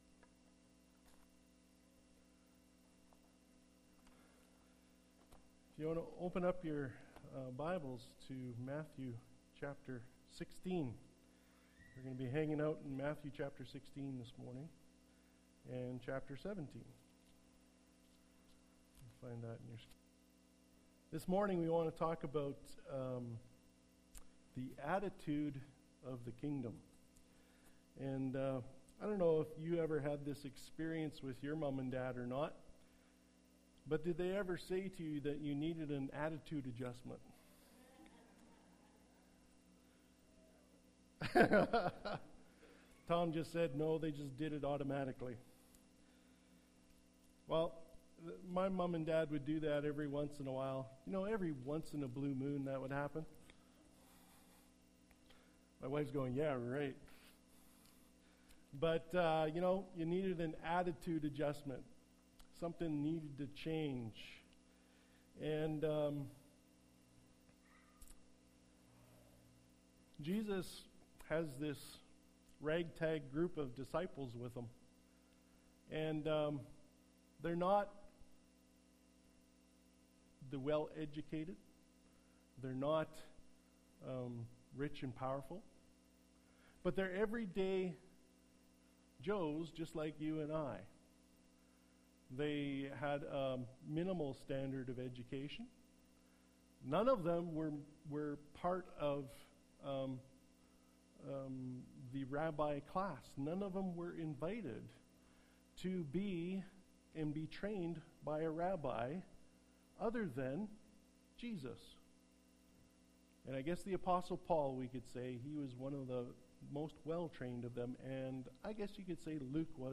Sermons | Leduc Alliance Church